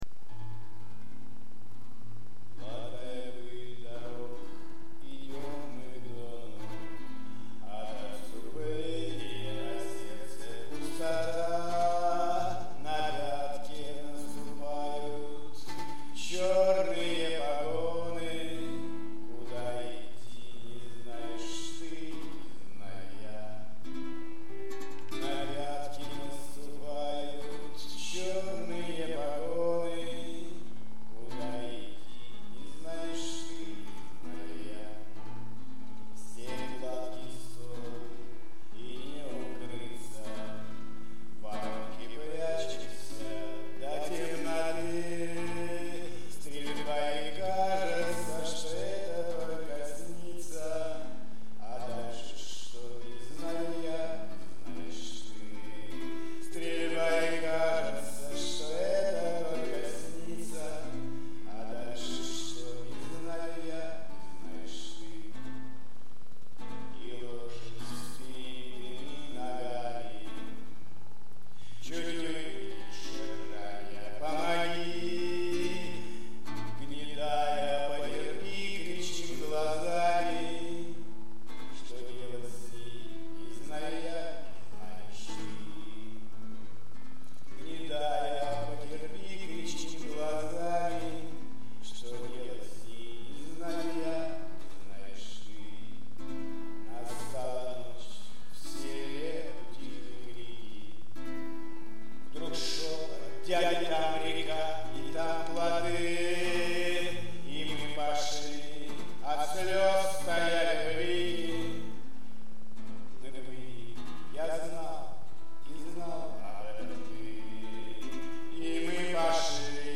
Музыкальный хостинг: /Шансон